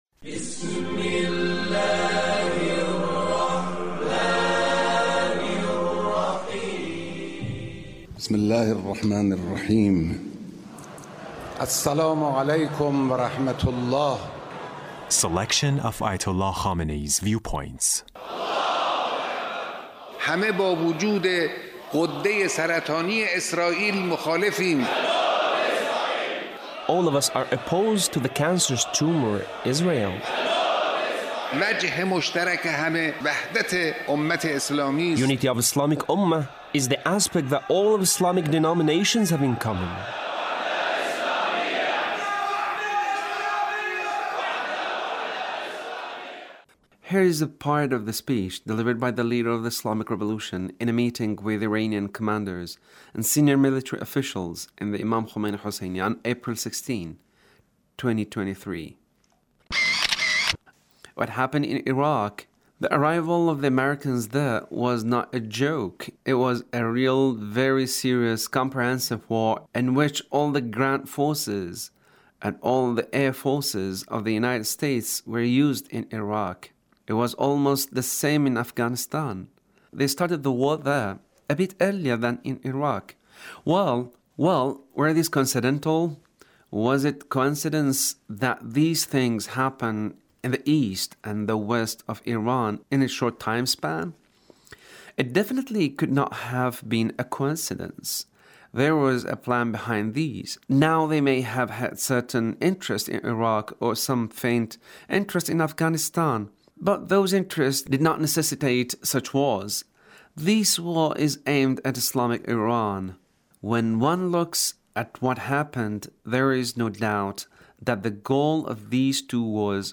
Leader's Speech Revolution in a meeting with Iranian commanders and senior military officials